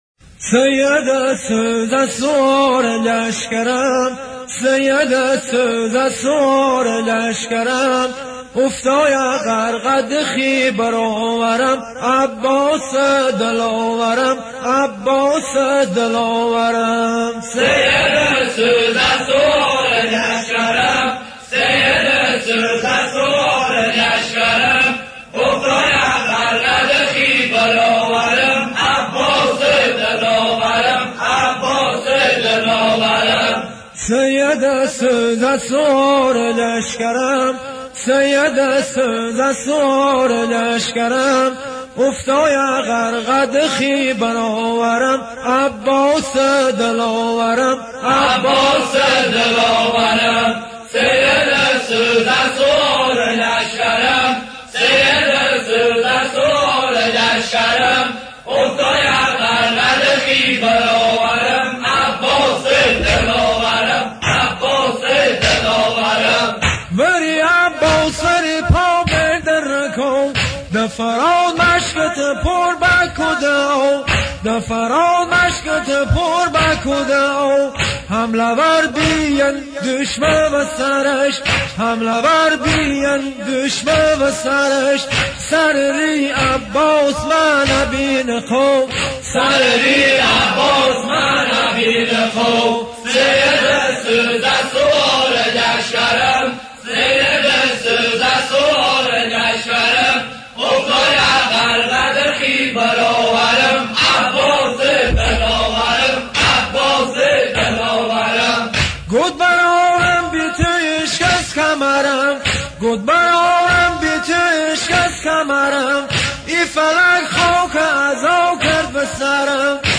دانلود و متن مداحی